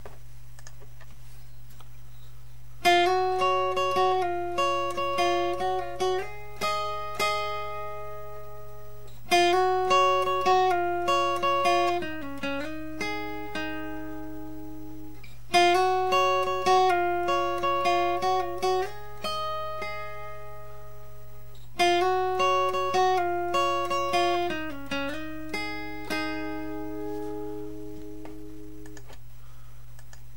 Its acoustic, so you can hear without the effects.